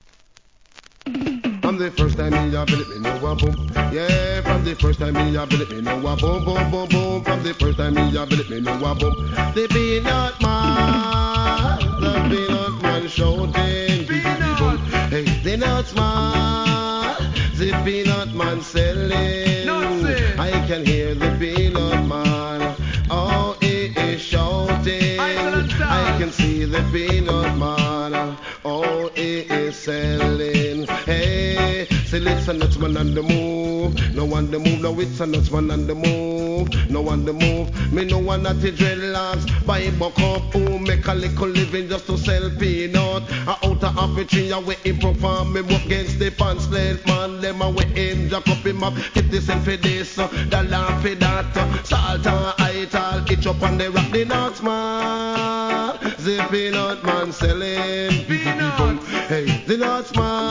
REGGAE
人気DeeJayのヒット!